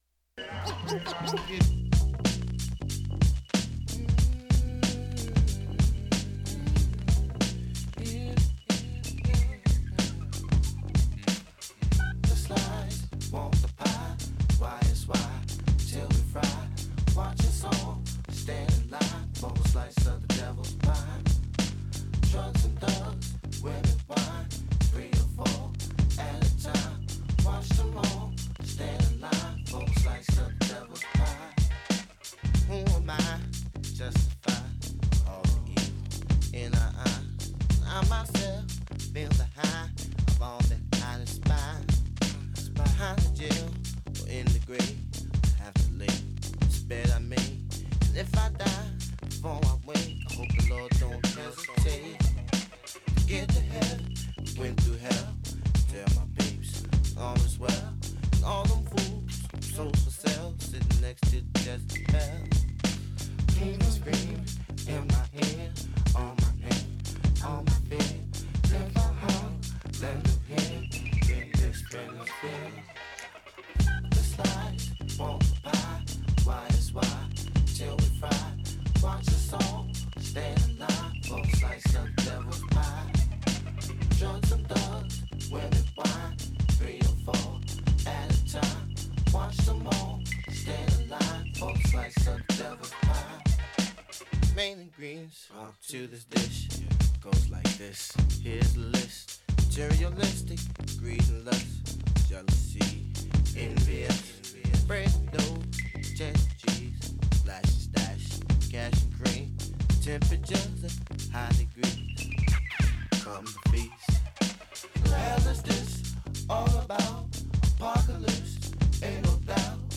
Free-form music program originating from Columbus, Ohio, often featuring local musicians and bands.